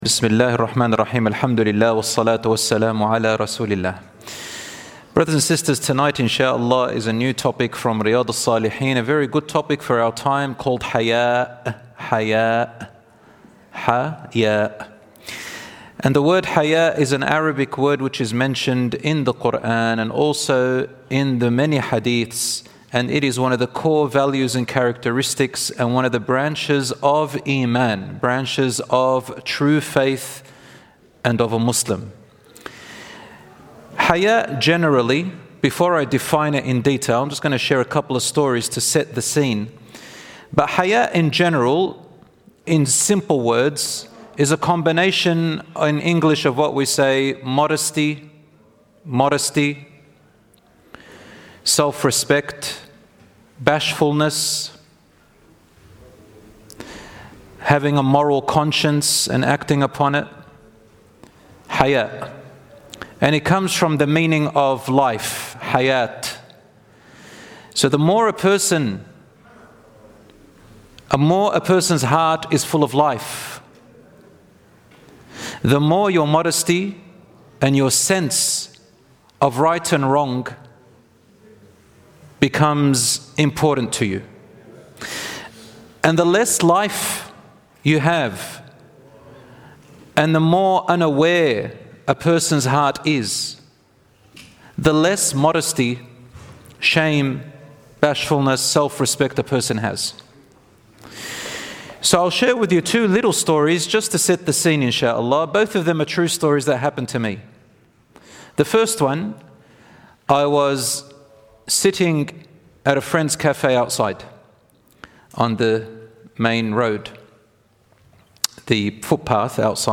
This lecture highlights how modesty influences our actions, strengthens our faith, and serves as a guiding light in a world full of challenges.